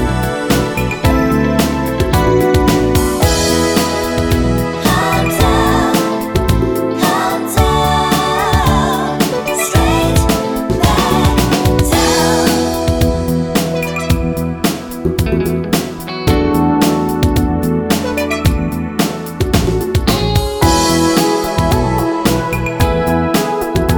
no sax Pop (1980s) 4:14 Buy £1.50